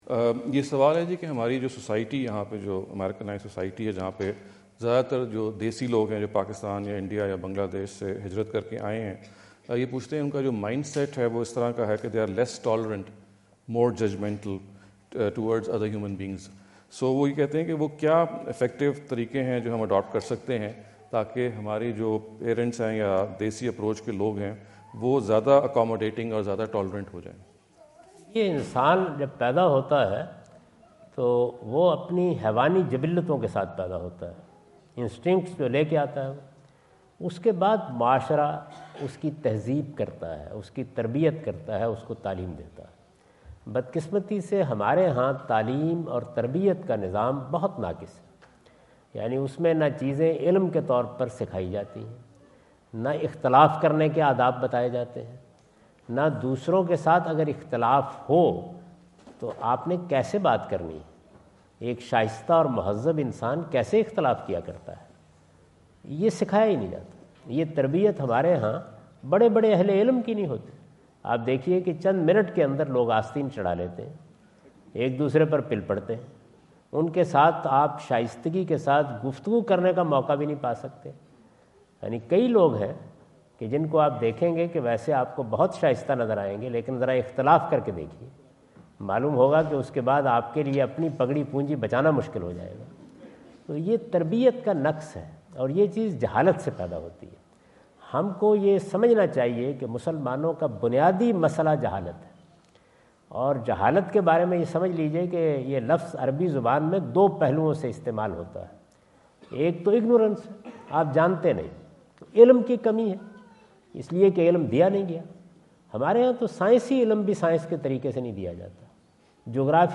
Javed Ahmad Ghamidi answer the question about "ٰPromoting Tolerance in the Society" asked at The University of Houston, Houston Texas on November 05,2017.